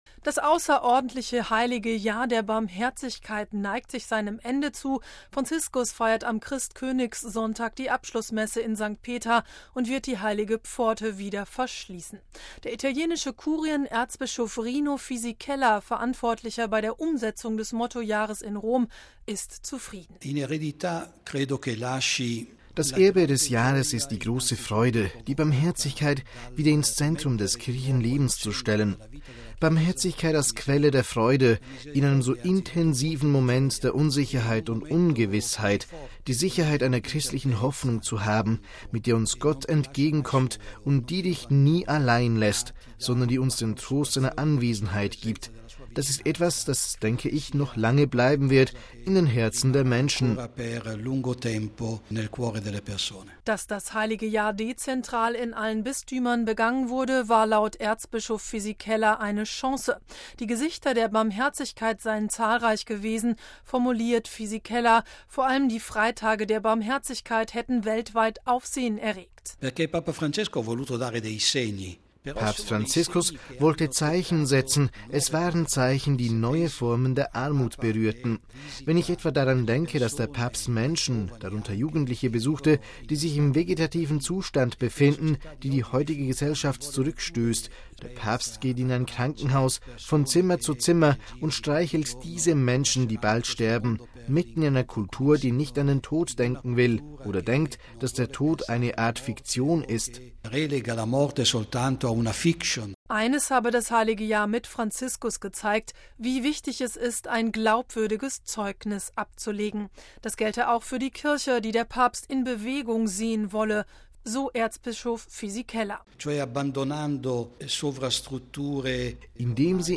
Radio Vatikan bat ihn zum Interview.